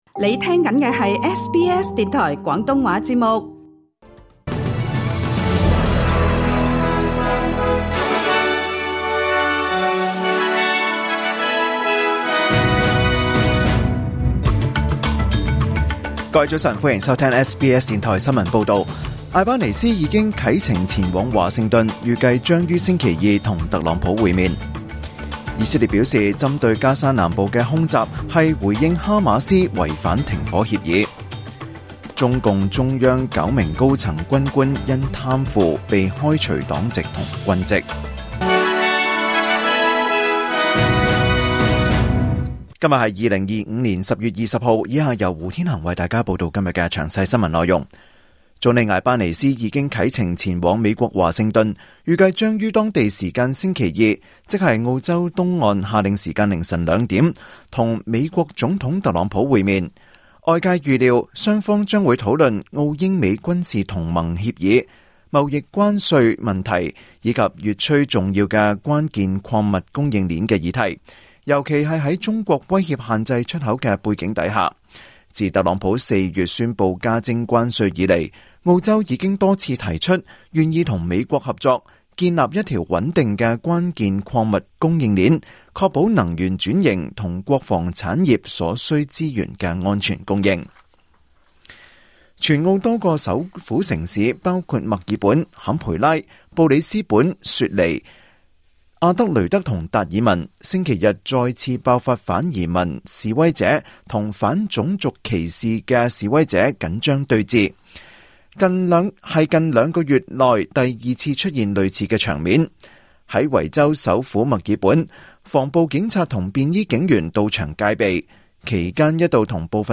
2025年10月20日SBS廣東話節目九點半新聞報道。